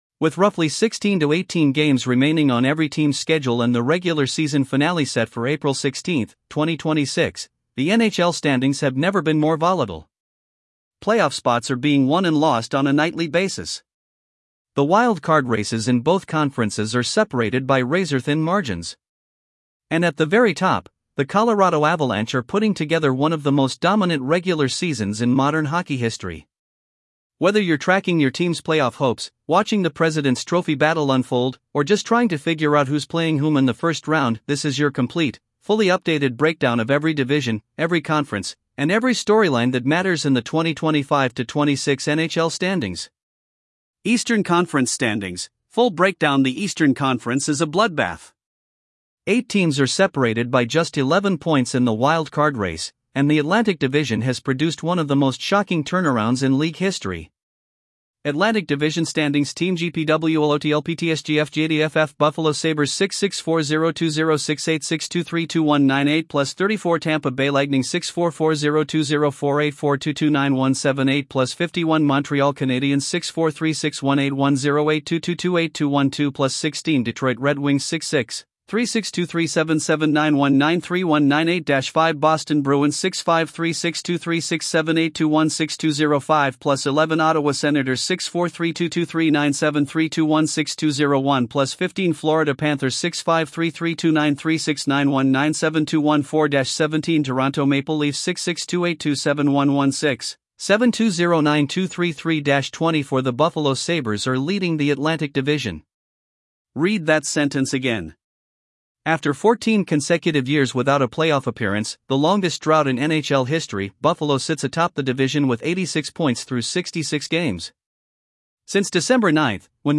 article-20-tts.mp3